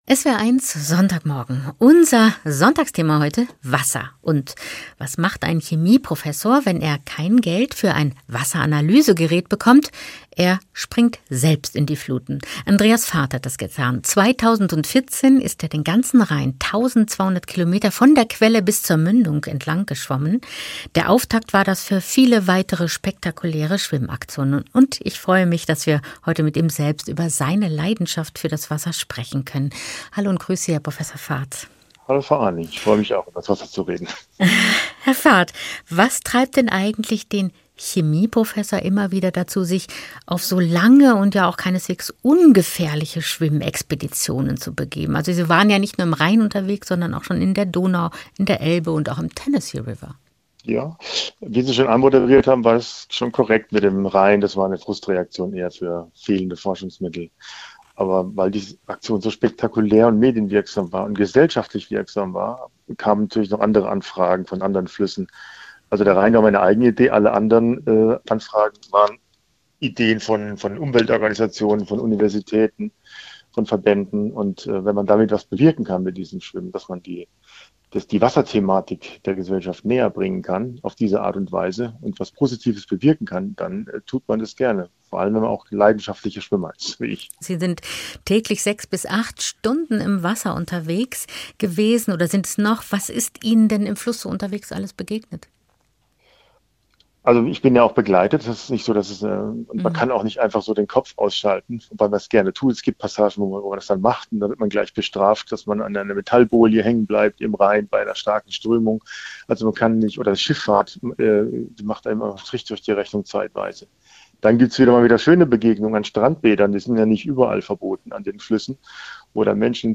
Ein Gespräch
SWR 1 Gespräch